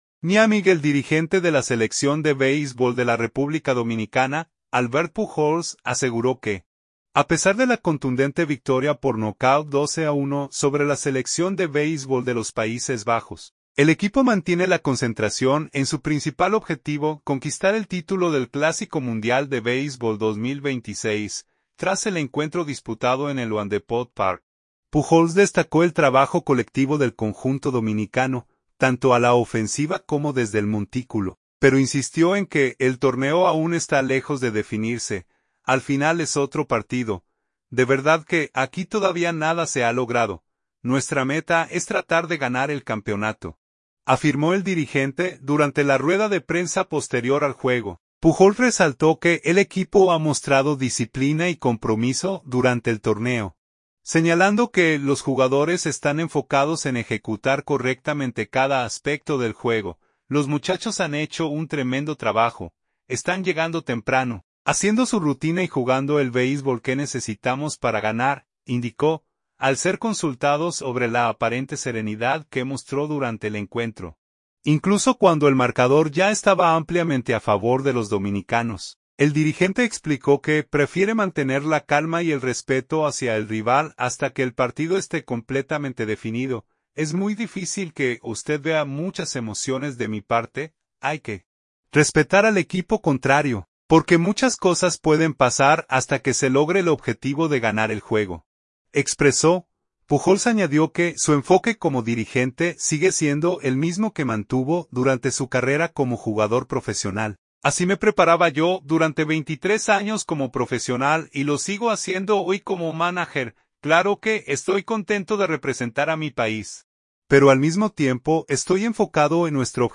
“Al final es otro partido. De verdad que aquí todavía nada se ha logrado. Nuestra meta es tratar de ganar el campeonato”, afirmó el dirigente durante la rueda de prensa posterior al juego.